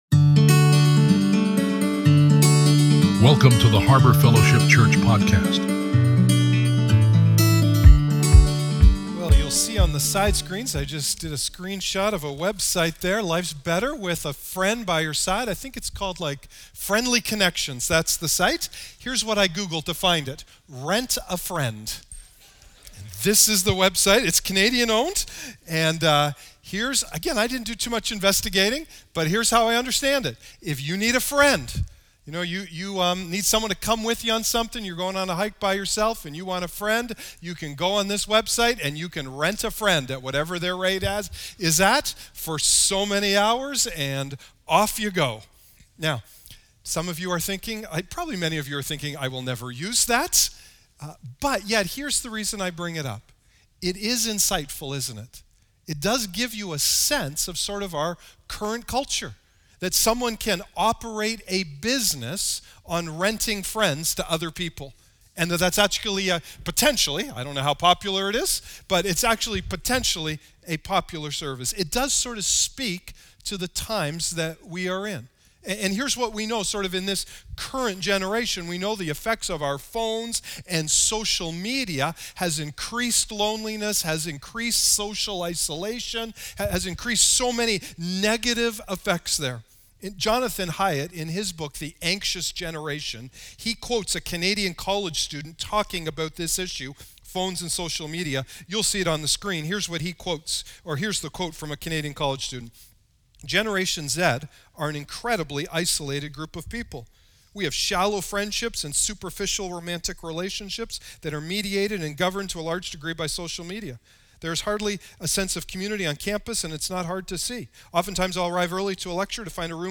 Sermons - Harbour Fellowship Church